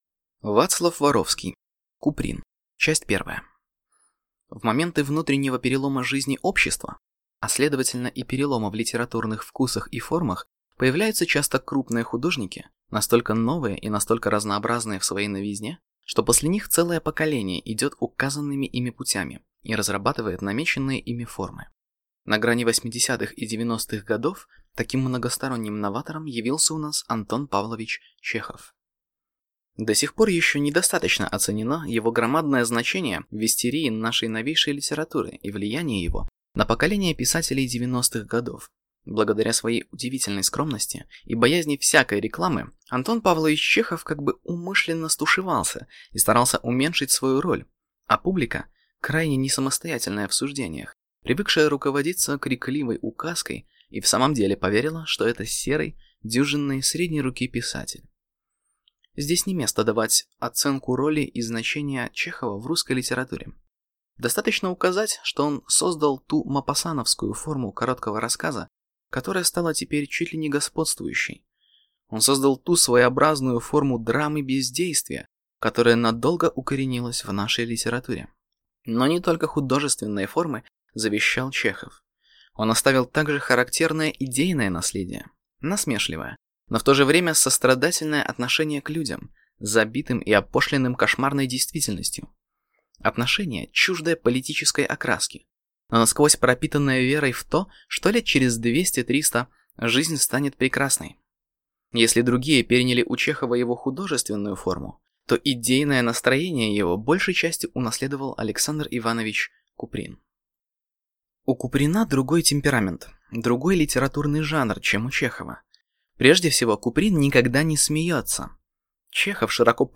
Аудиокнига Куприн | Библиотека аудиокниг